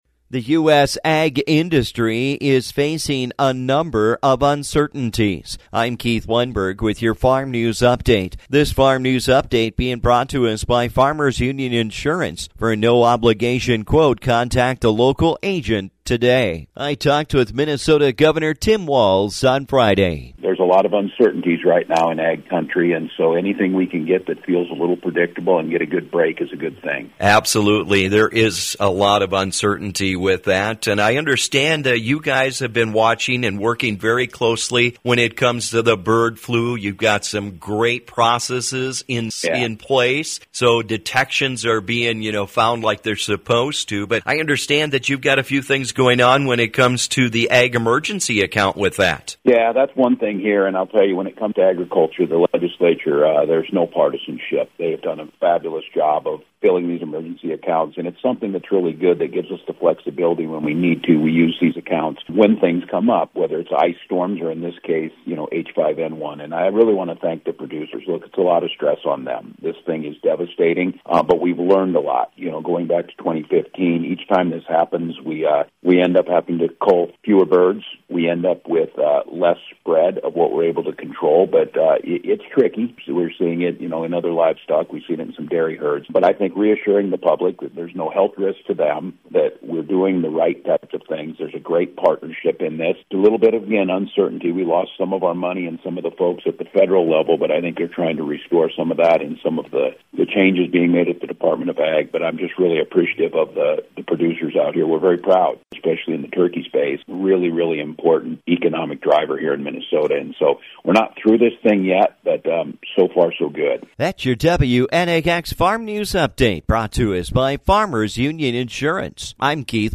The U.S. Ag Sector has been dealing with a number of challenges and today I talk with Minnesota Governor Tim Walz about some of them.